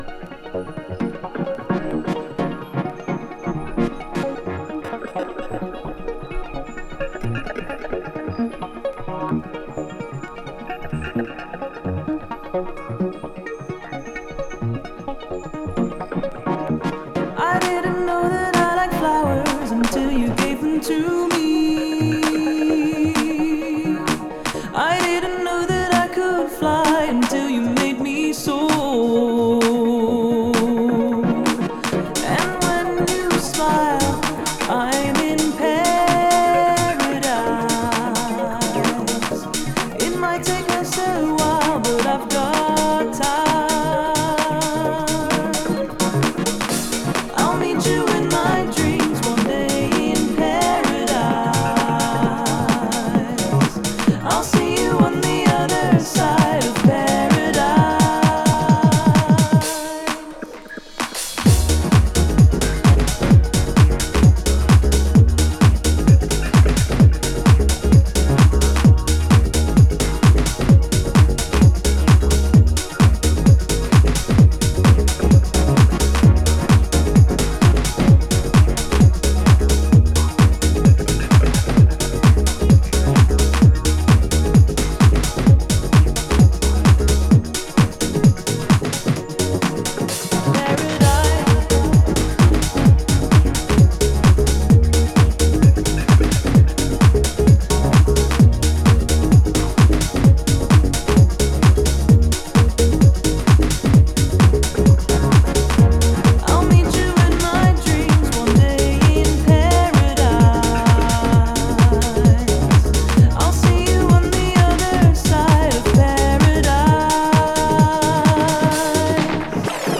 Styl: House, Techno, Minimal